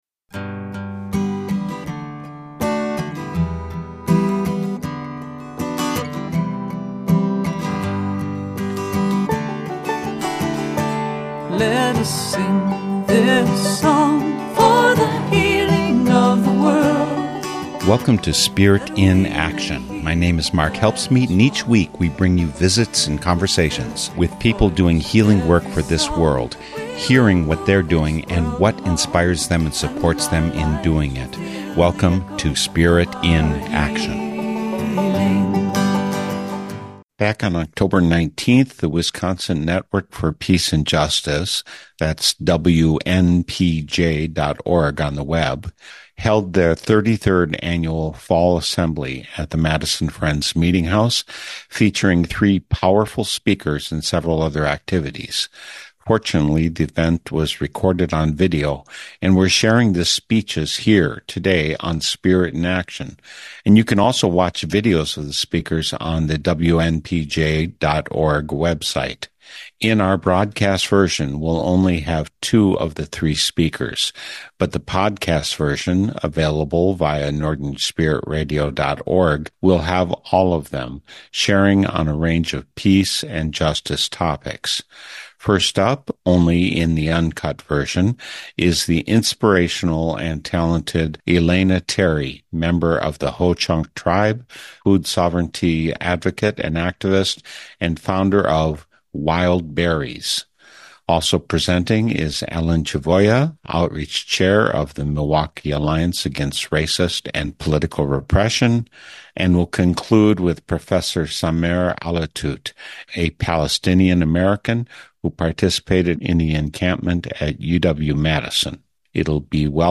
Three speeches from the October 19th meeting of the Fall Assembly of the Wisconsin Network for Peace and Justice. In our broadcast version we'll have only two of the three speakers, but the podcast version will have all three of them, sharing on a range of peace and justice topics.